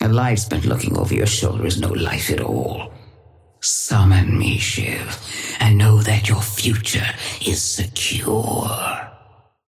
Patron_female_ally_shiv_start_05.mp3